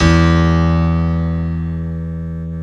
Index of /90_sSampleCDs/Best Service ProSamples vol.10 - House [AKAI] 1CD/Partition D/SY PIANO